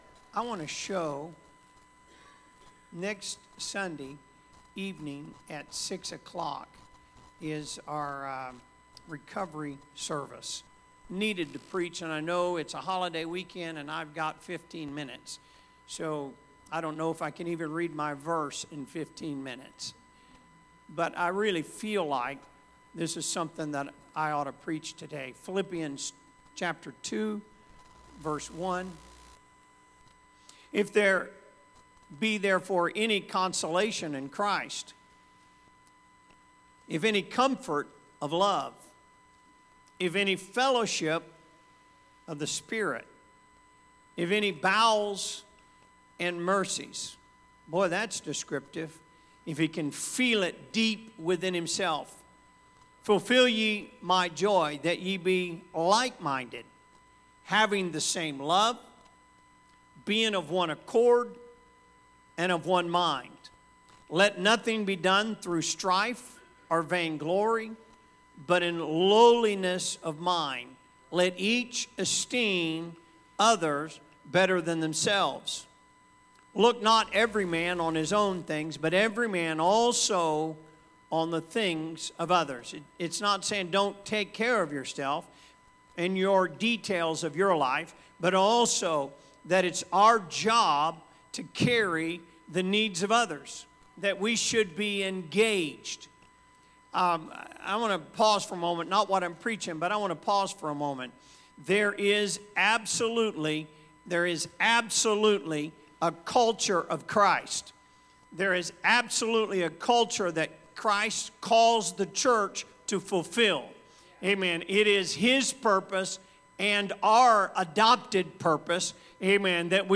Sunday Service - Part 7